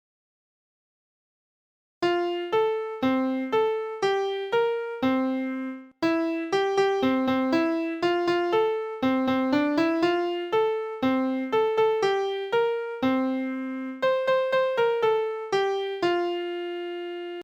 This song is sung in two parts.